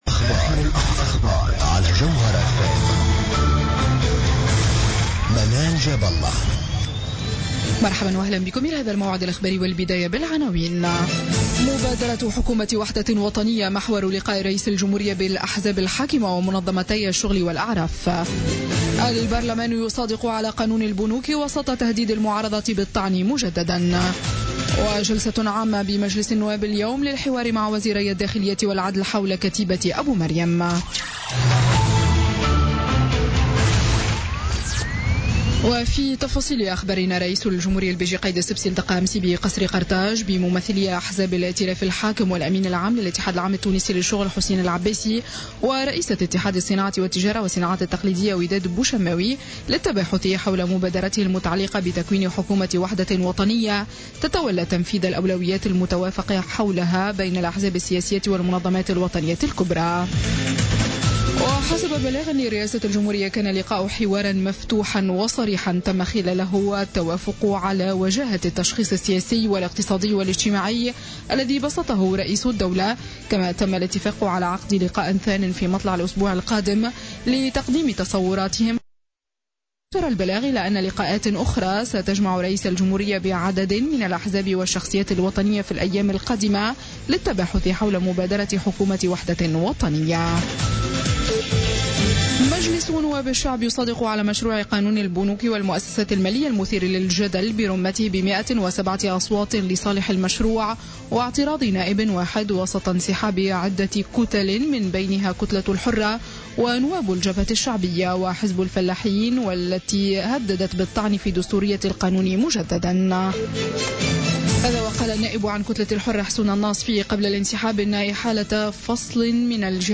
Journal info 00h00 du vendredi 10 juin 2016